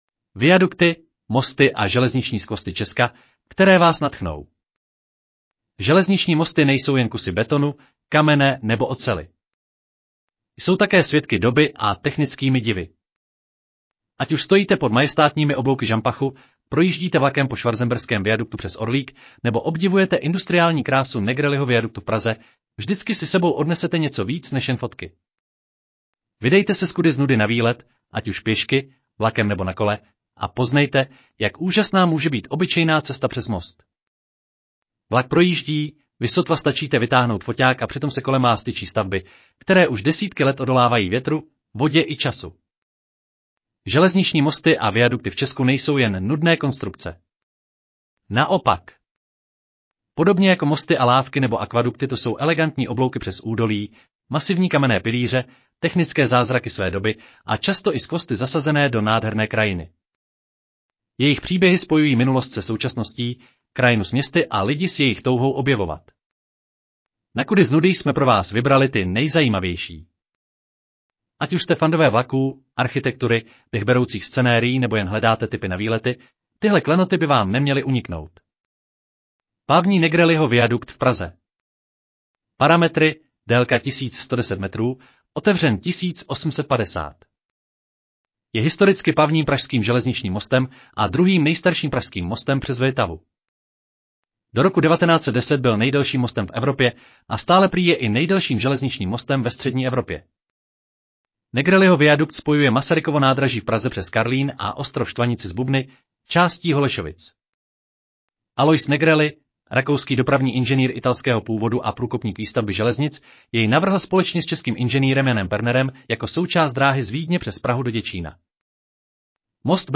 Audio verze článku Viadukty, mosty a železniční skvosty Česka, které vás nadchnou